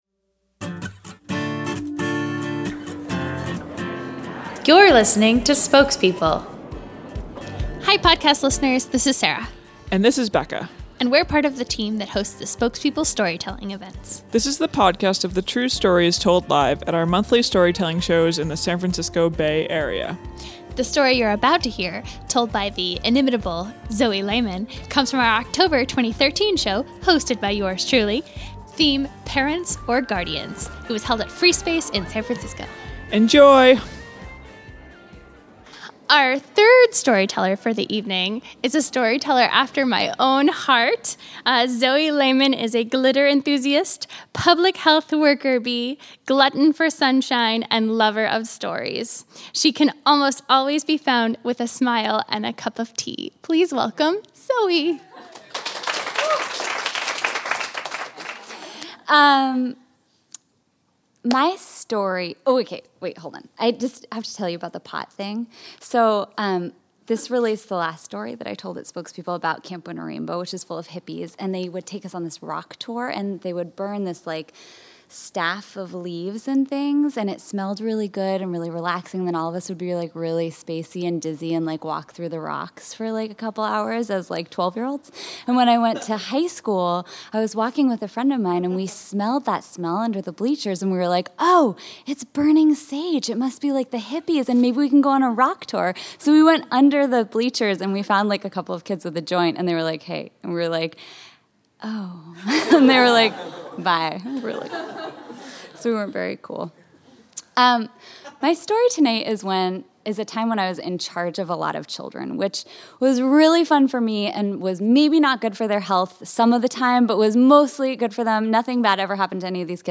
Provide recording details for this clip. She was just floored to discover part of a Halloween haunted house that left her and her band of third graders in the in-between of an elevator shaft. This story was told live at our Oct. 2013 show, “Parents or Guardians.”